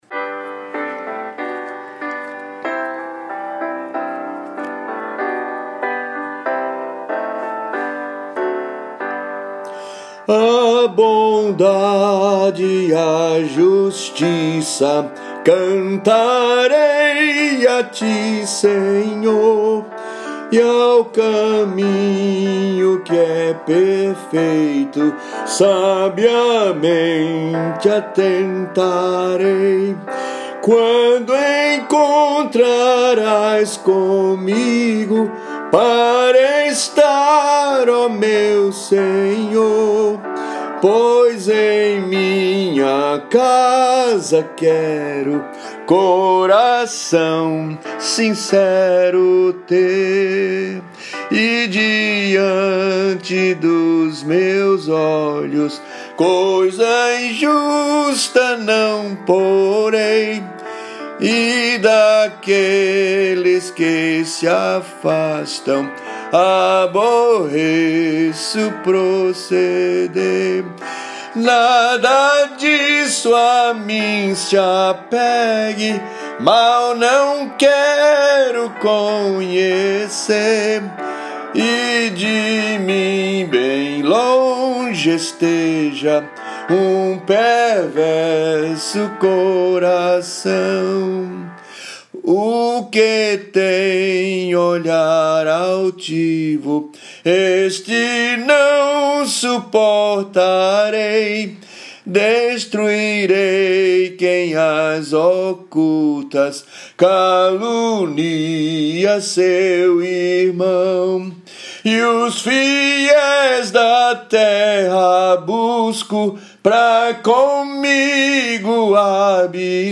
salmo_101B_cantado.mp3